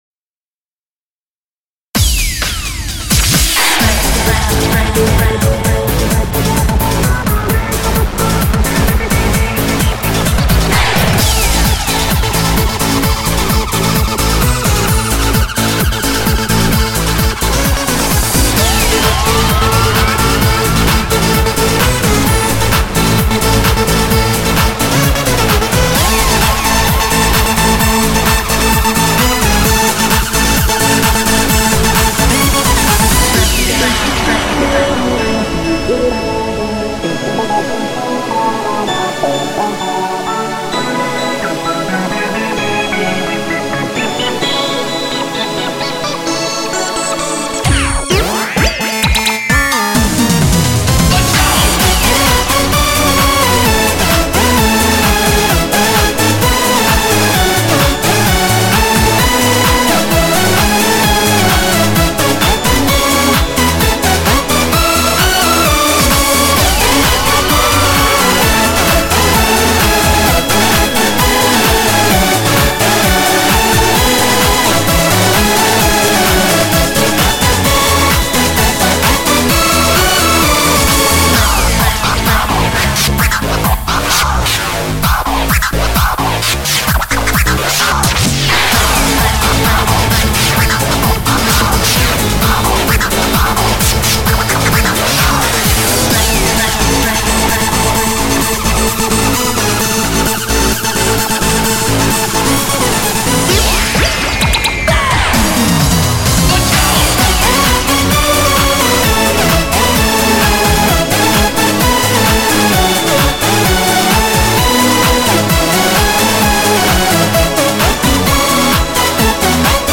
BPM130
Audio QualityPerfect (High Quality)
Comments[Bubblegum Dance]